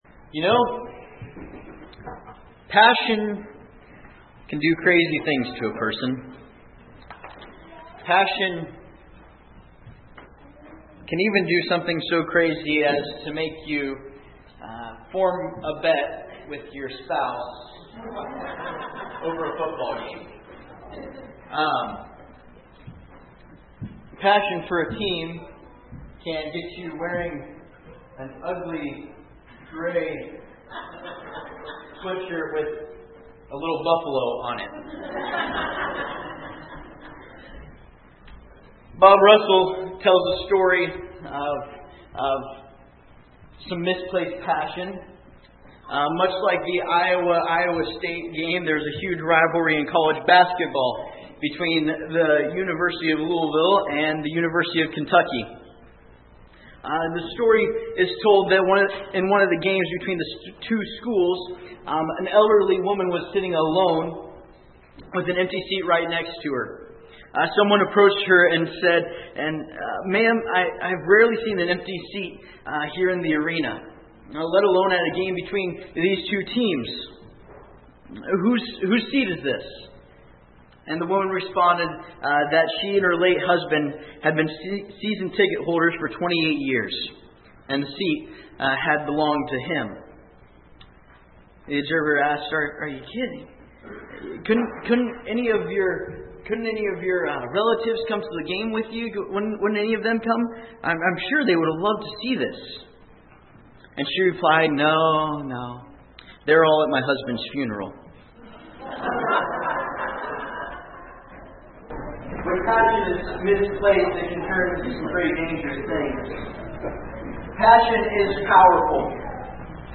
Passage: Exodus 2:11-17; 3:11,13; 4:1,10,13; 6:30 34:29-35; 12:21; 13:3; 32:9-14 Service Type: Sunday Morning Topics: Change , Passion « What’s Your Change?